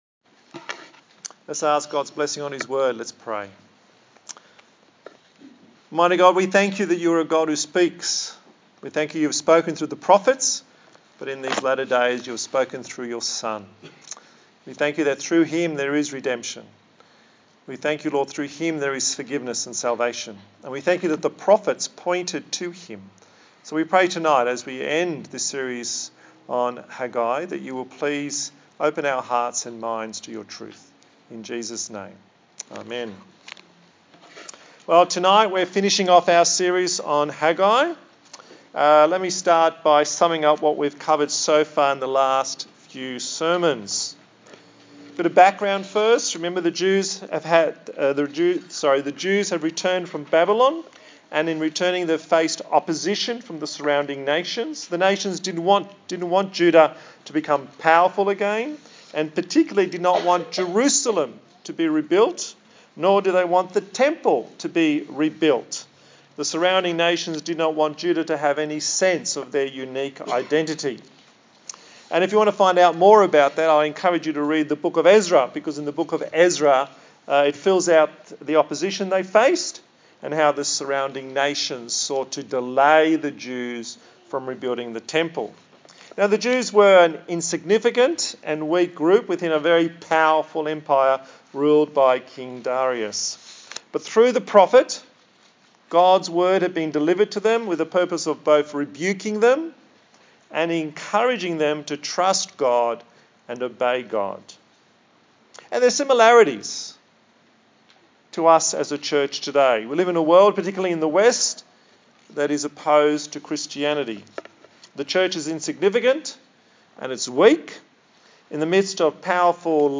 A sermon in the series on the book of Haggai